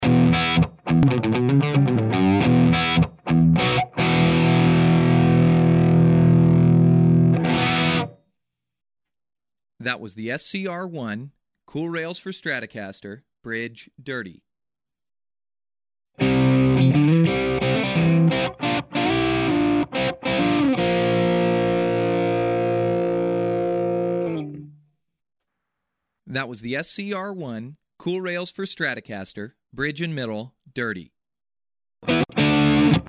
Comme sur les humbuckers ils sont assez peu sensibles au bruit.
Position chevalet clean télécharger, saturé
SHR1BrigeMidDirty.mp3